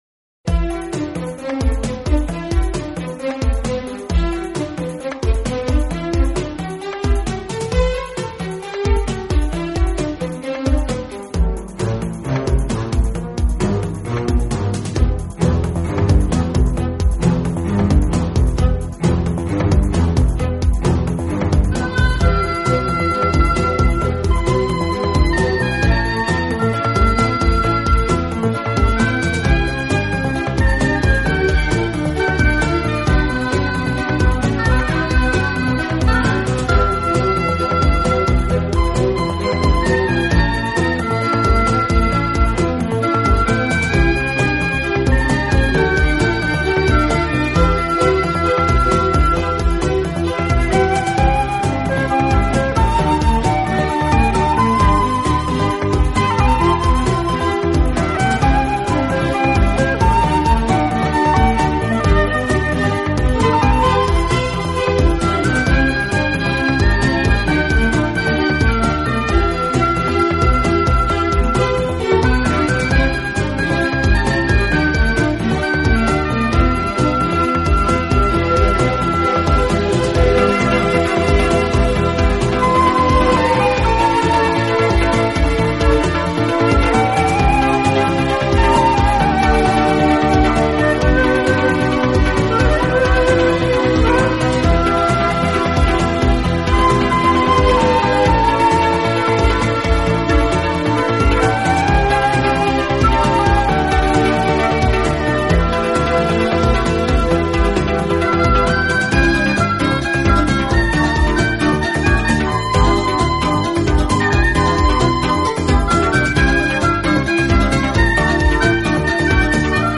的音乐总是给人那幺大气、庄严和堂皇的感觉。